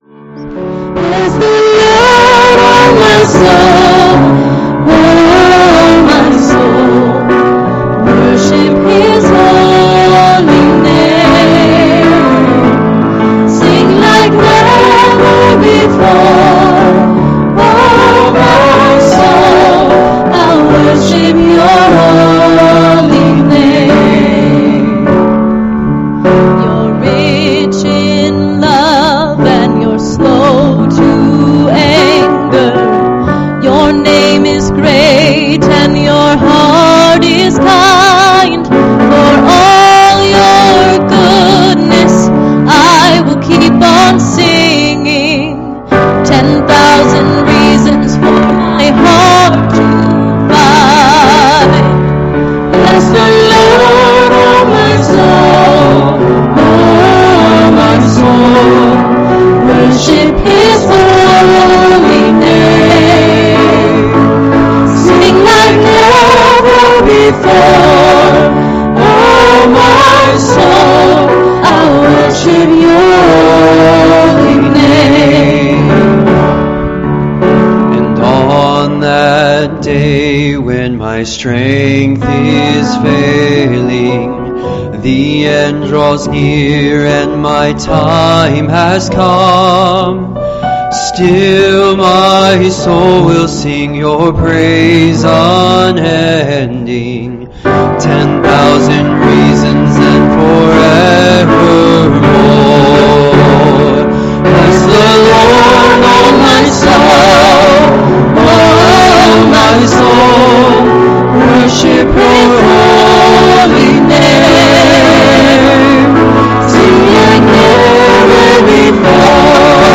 Please, click the the arrow below to hear this week's service.